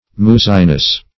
Muzziness \Muz"zi*ness\, n. The state or quality of being muzzy.
muzziness.mp3